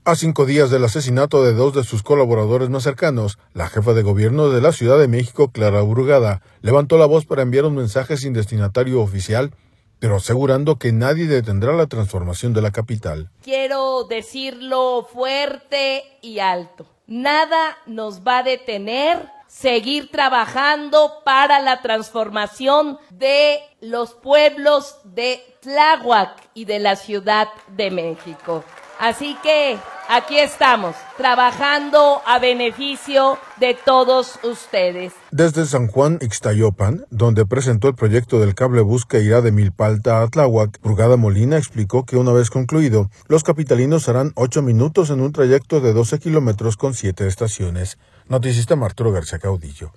Desde San Juan Ixtayopan, donde presentó el proyecto del cablebús que irá de Milpa Alta a Tláhuac, Brugada Molina explicó que una vez concluido, los capitalinos harán 8 minutos en un trayecto de 12 kilómetros con siete estaciones.